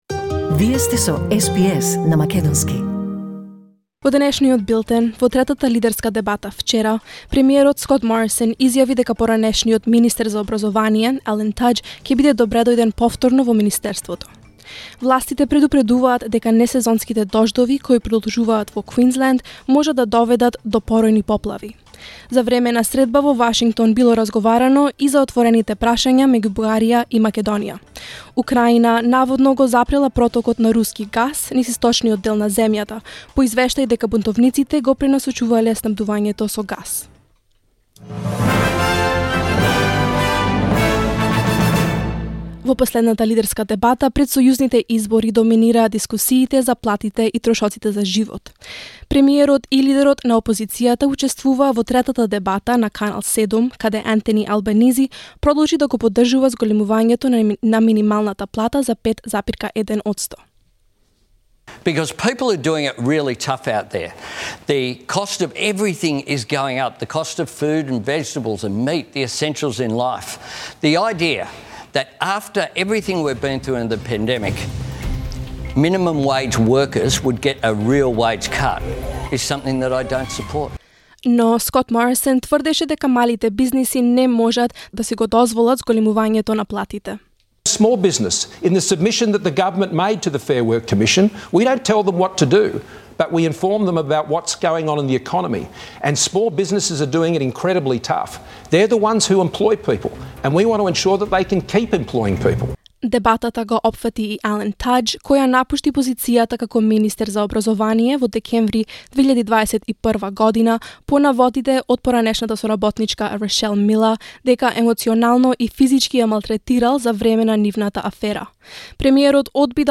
SBS News in Macedonian 12 May 2022